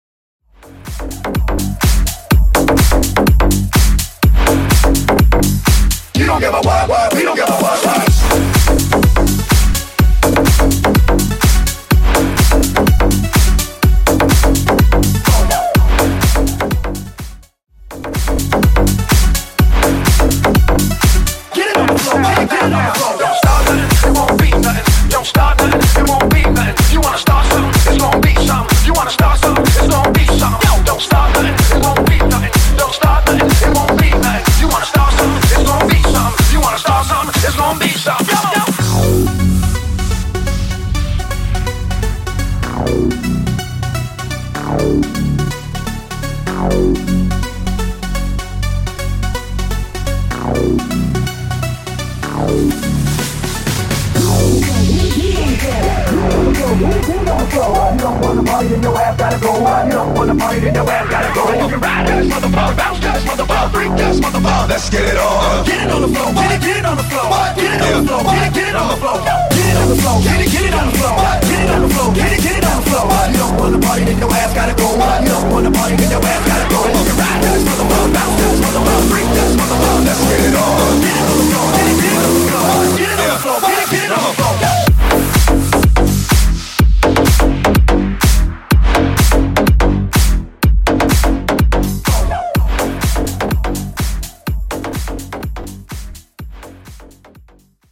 Clean BPM: 128 Time